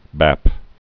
(băp)